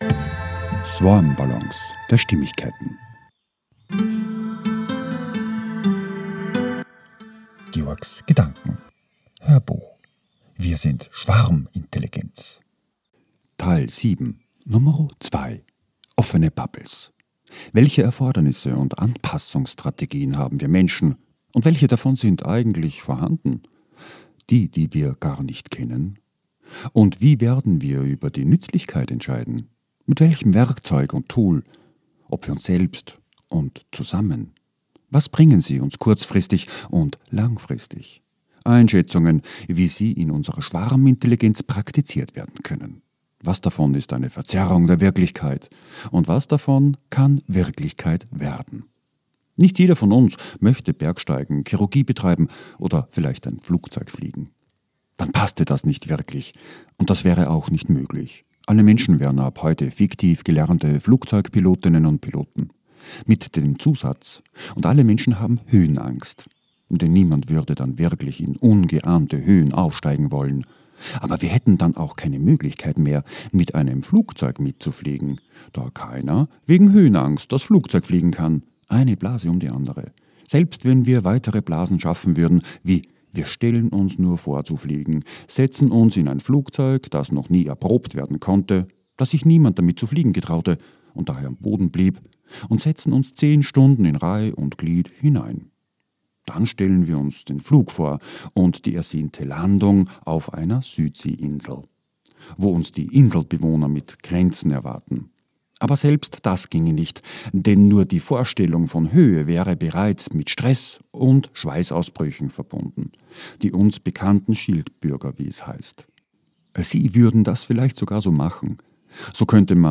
HÖRBUCH - 007.2 - WIR SIND SCHWARMINTELLIGENZ - OFFENE BUBBLES